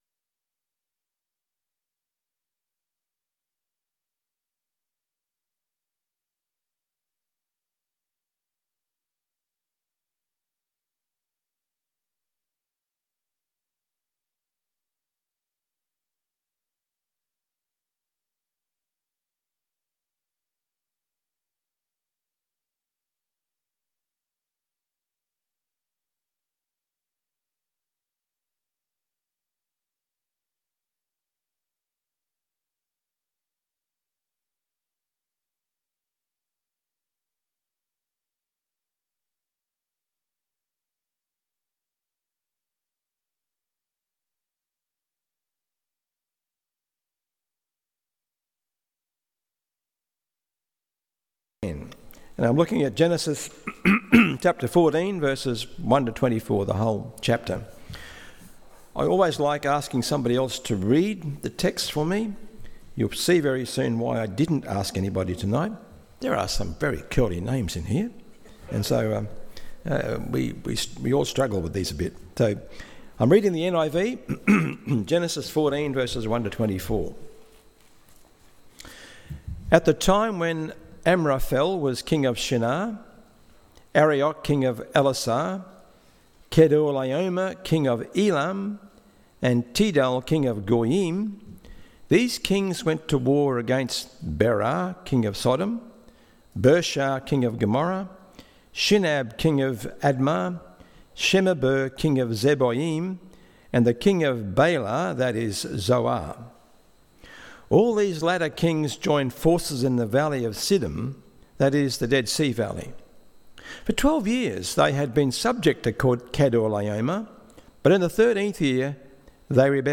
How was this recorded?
Abram Meets The Mystery Man PM Service